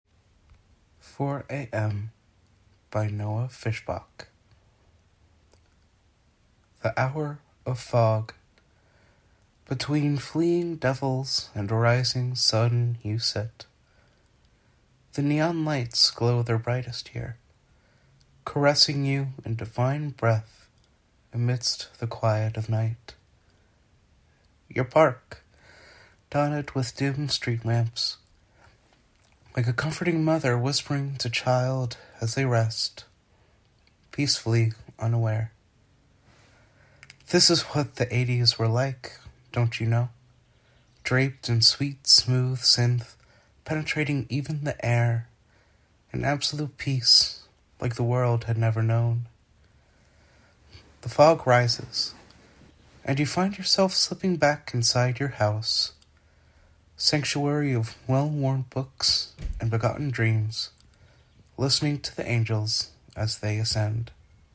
(listen to the poem, read by the author)